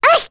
Luna-Vox_Damage_01.wav